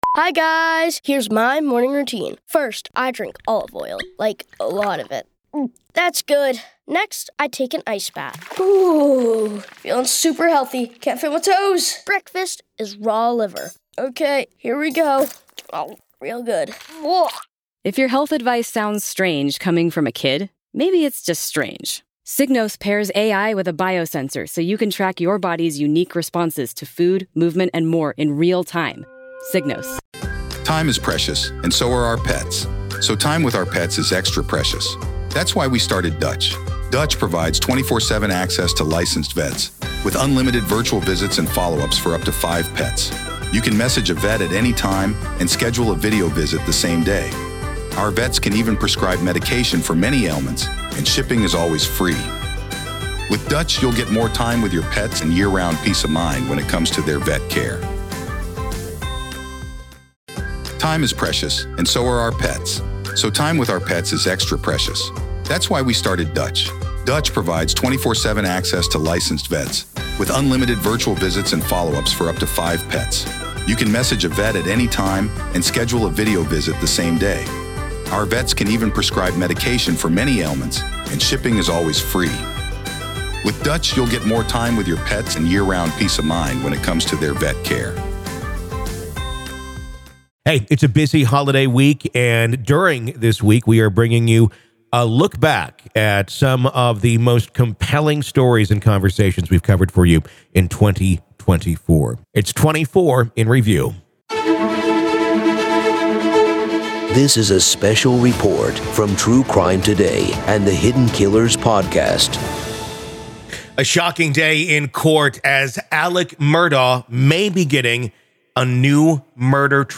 LISTEN To Full Alex Murdaugh Juror Testimony Stating Becky Hill DID Influence Her Verdict-2024 Year in Review